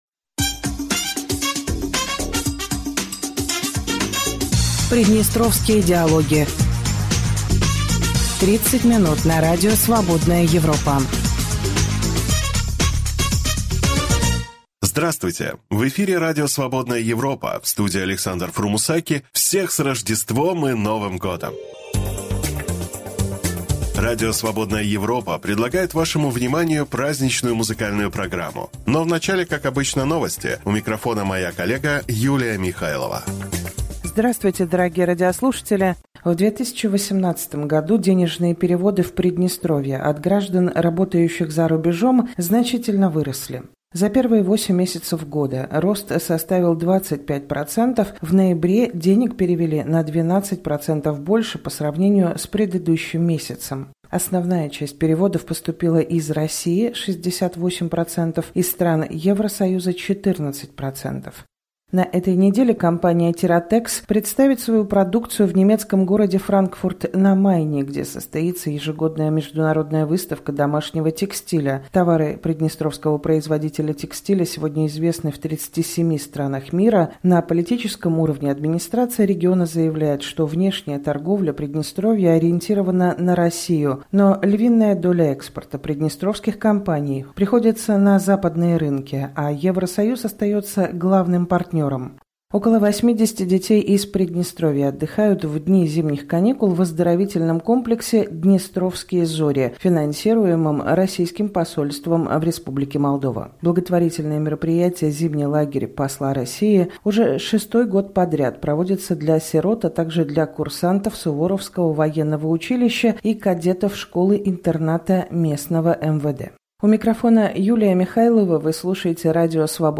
По традиции, в период зимних праздников Радио Свободная Европа приглашает вас послушать специальную музыкальную программу